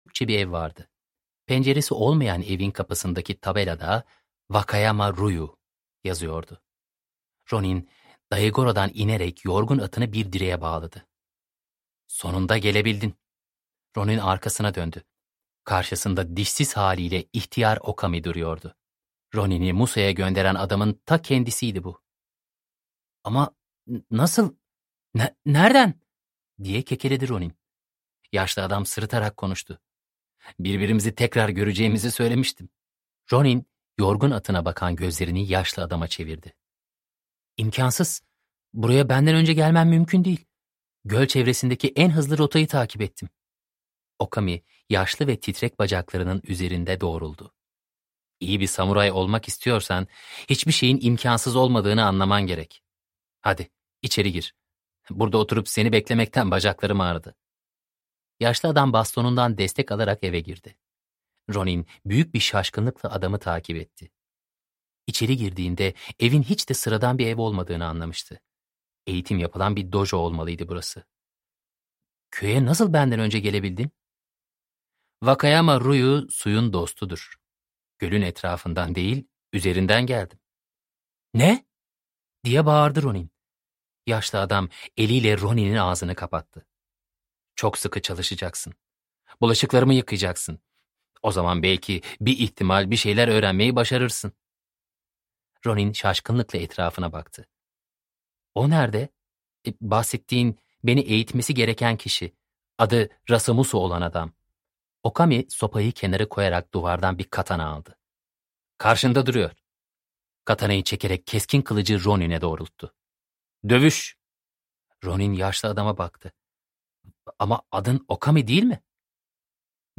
Ronin 2 - Yay - Seslenen Kitap